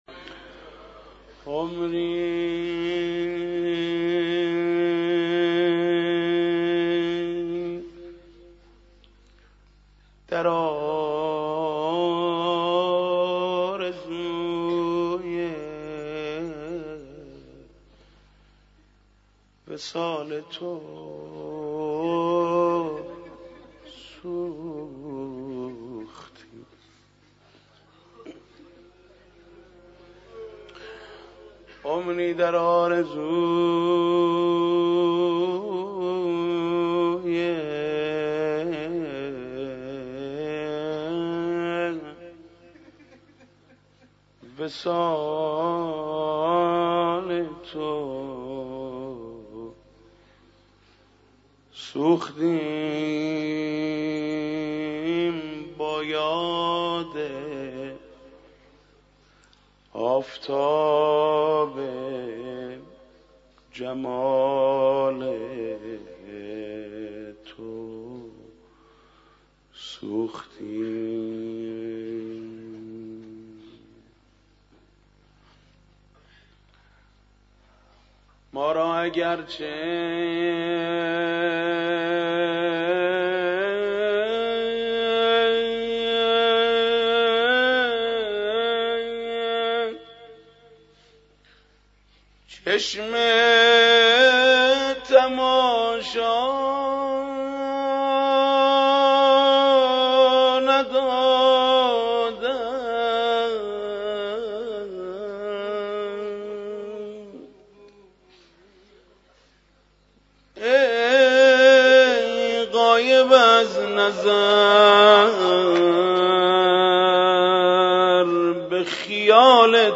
مناجات با امام زمان عجل الله